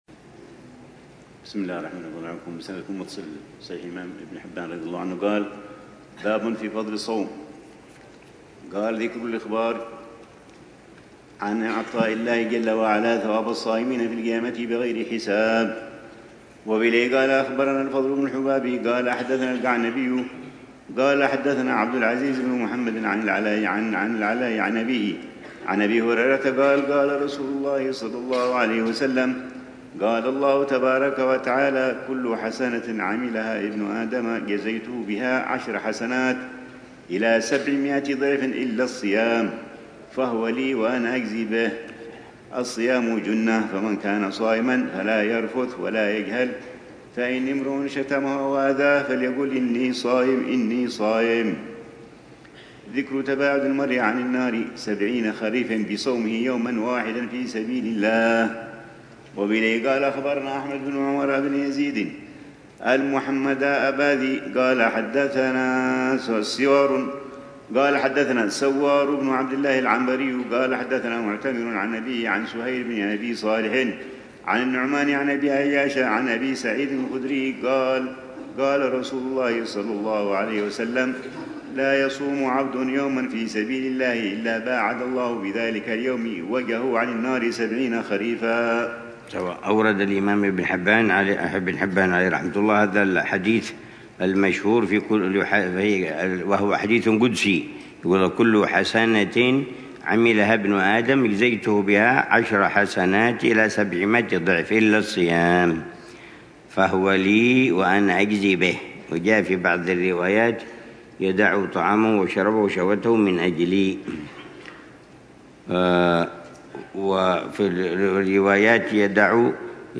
الروحة الرمضانية الرابعة بدار المصطفى لعام 1446هـ ، وتتضمن شرح الحبيب العلامة عمر بن محمد بن حفيظ لكتاب الصيام من صحيح ابن حبان، وكتاب الصيام